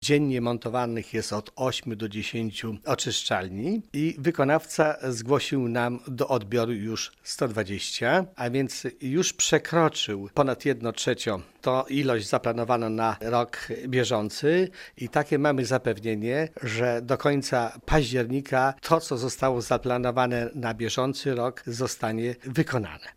„Teraz prace idą pełną parą, a tegoroczny plan ma być wykonany wcześniej niż zakładaliśmy” - mówi zastępca wójta Gminy Łuków Wiktor Osik: